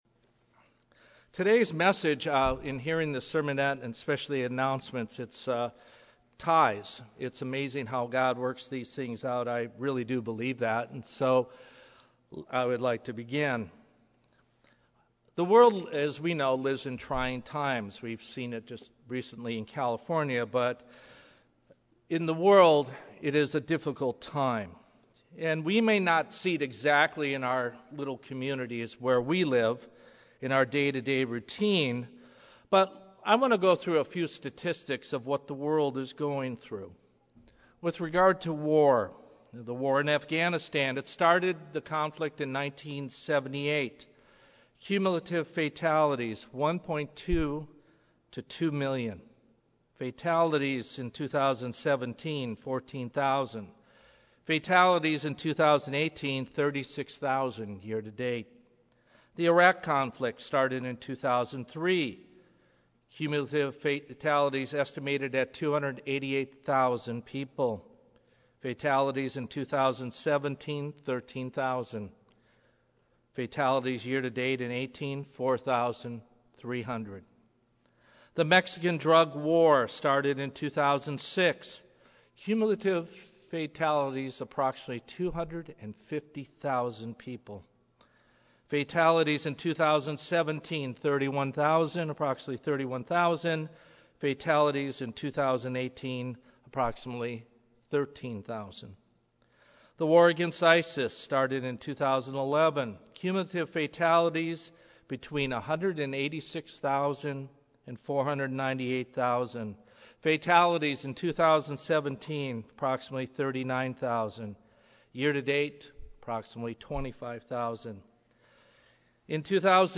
Sermons
Given in Los Angeles, CA